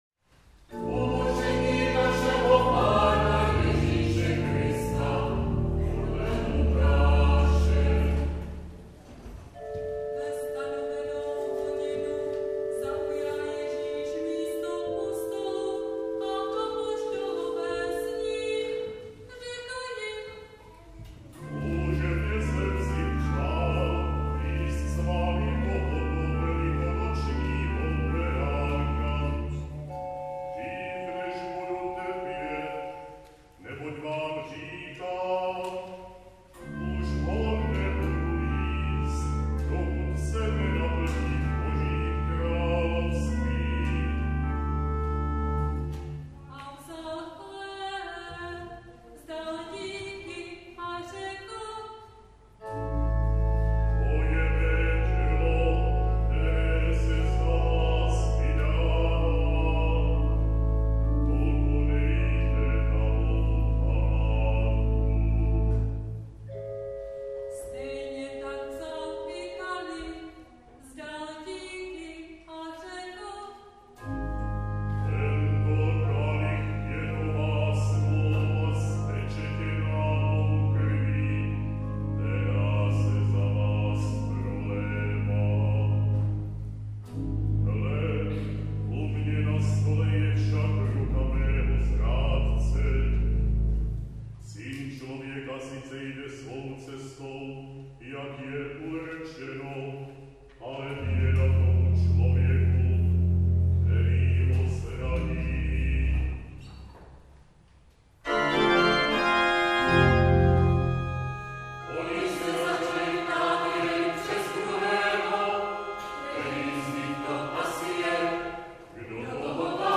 Bazilika, Svat� Hora, P��bram, ned�le 13. dubna 2025 v 11:00
varhany